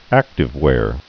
(ăktĭv-wâr)